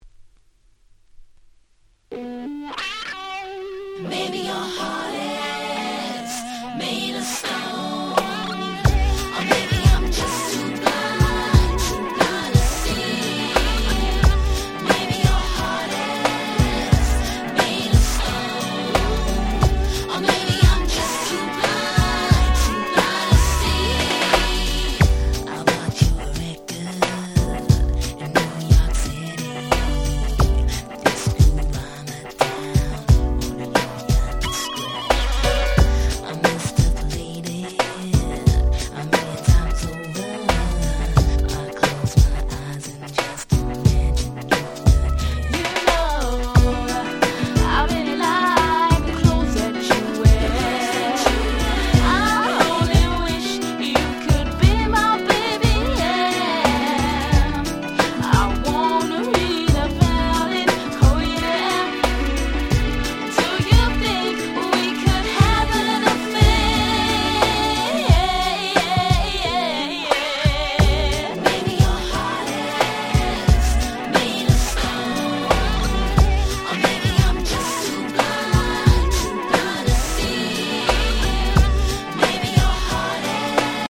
98' Nice EU R&B !!